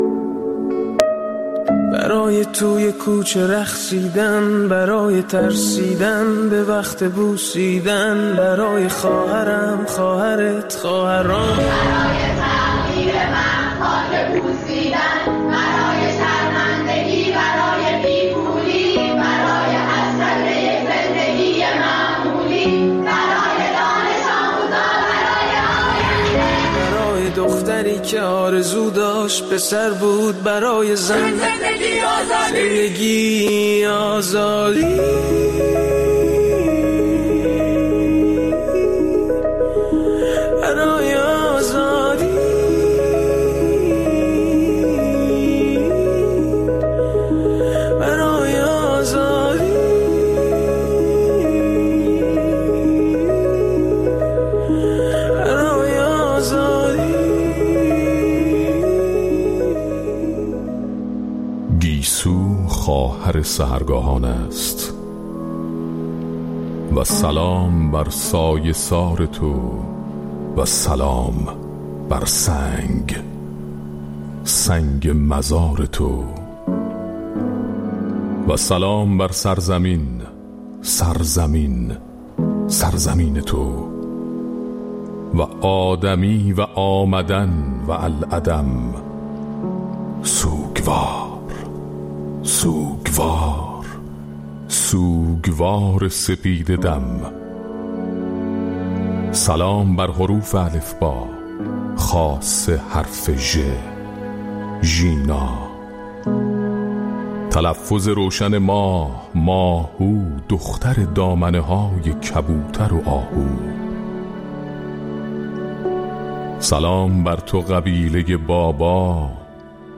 در این ایستگاه فردا، نظرات شنوندگان ایستگاه فردا را در مورد گسترش حرکت اعتراضی «عمامه‌پرانی» در نقاط مختلف ایران می‌شنویم.